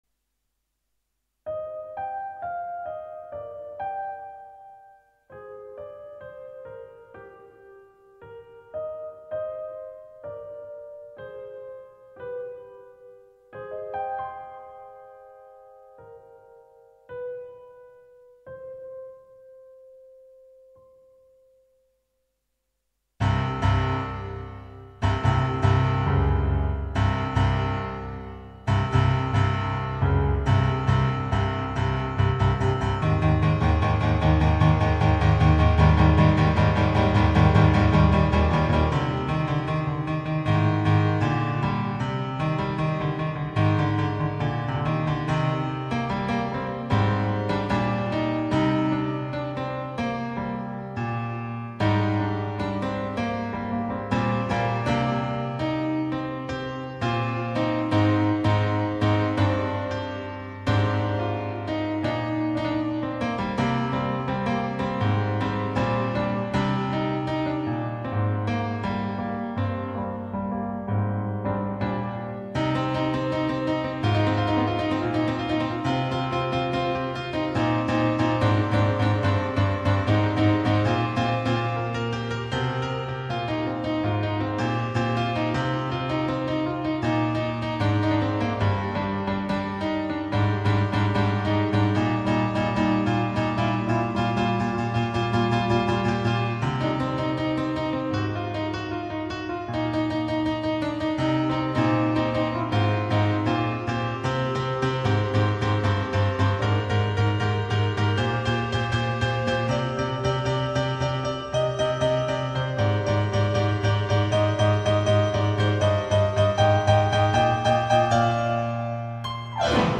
An improvisation on the theme of Nightmare Moon/Luna. Instrument used: Roland AX-Synth keytar It's pretty rough around the edges, but there were enough good moments in this to warrant uploading it.